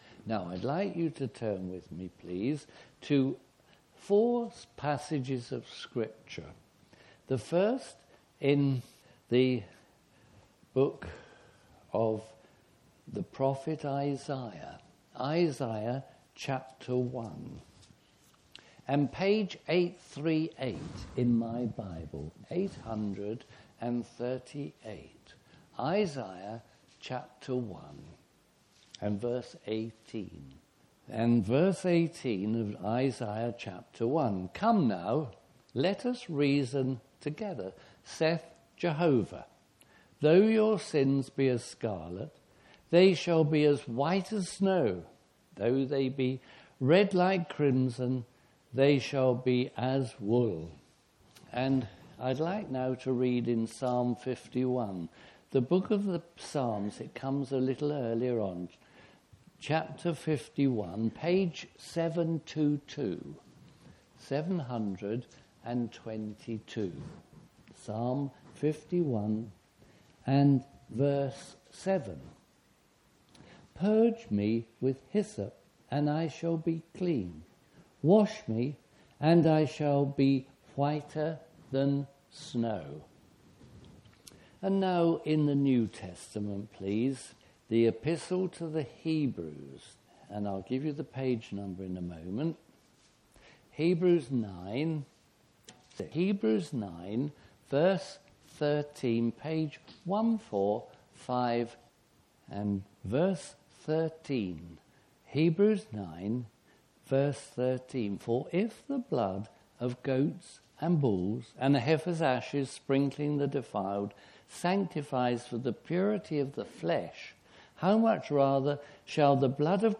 Gospel Preachings